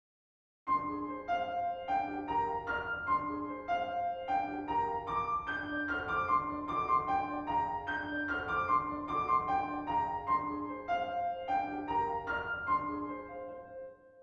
Lyceion dances
She ironically resumes her dance, which, at first more languorous, becomes steadily more animated until the end.